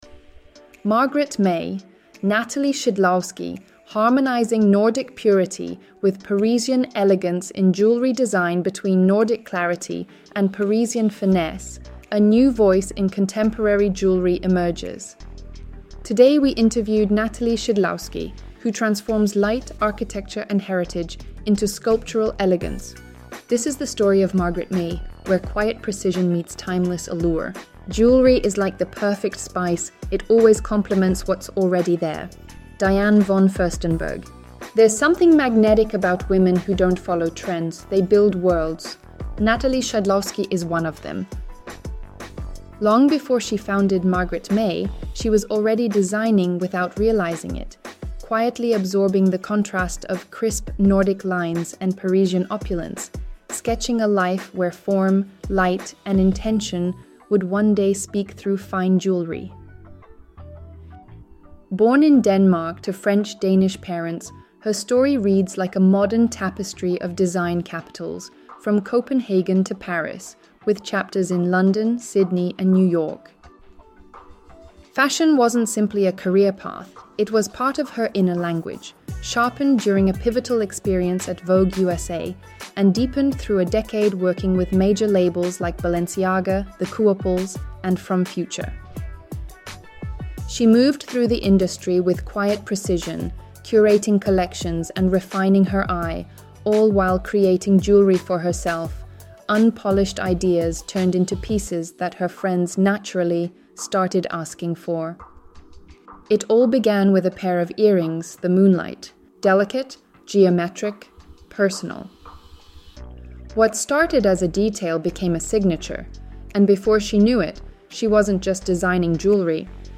Très belle cérémonie à Zagreb (Croatie) le 10 juin 2014 à l'occasion de la parution de l'autobiographie de la grande chanteuse croate Tereza Kesovija entourée de nombreuses personnalités, amis et famille.